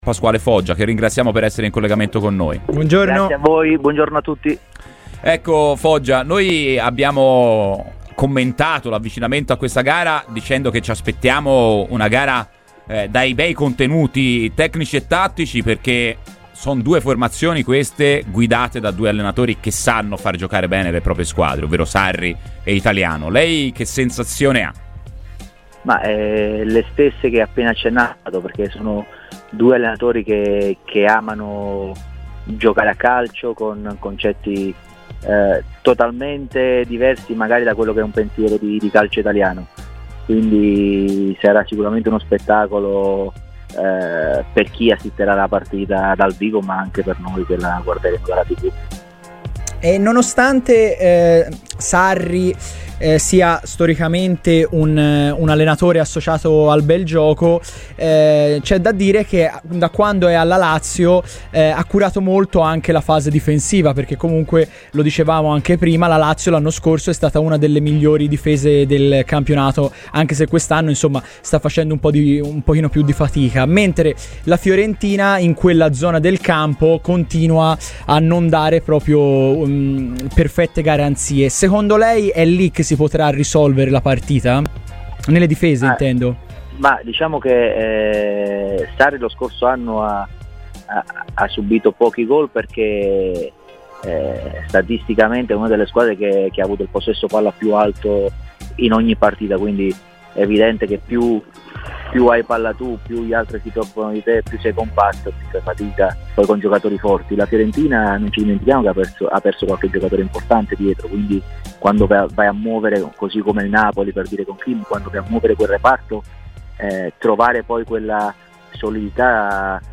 Pasquale Foggia, ex biancoceleste, ha parlato verso Lazio-Fiorentina su Radio FirenzeViola: “Sono due allenatori che amano giocare a calcio, con concetti totalmente diversi dal calcio italiano.